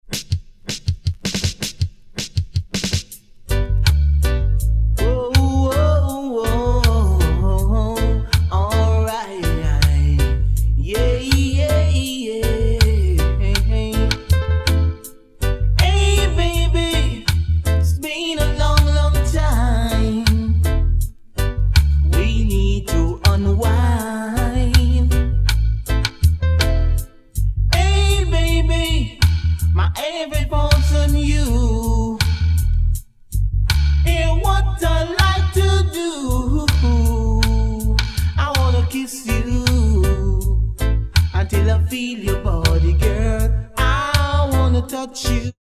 Dub Version